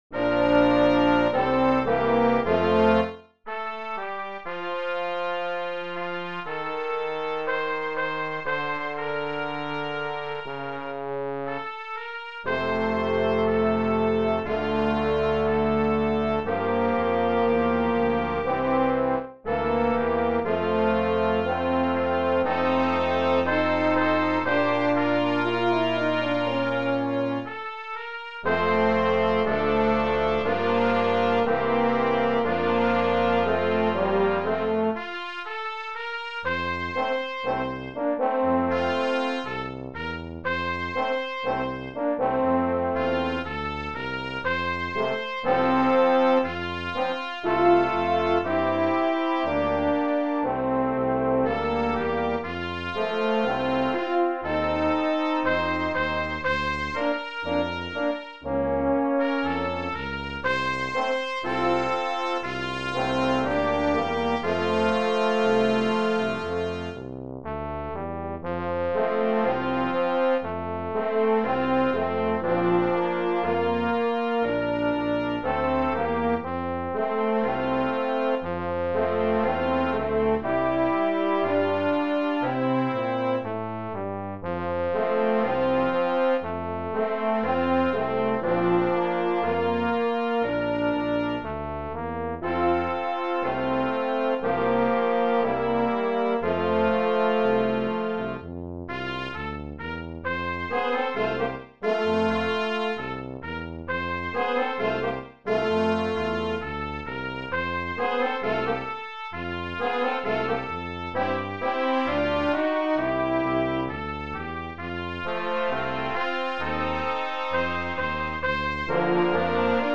Brass Quintet
Traditional Dixieland standard